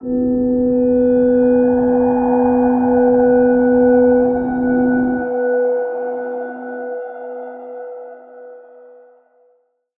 G_Crystal-B4-f.wav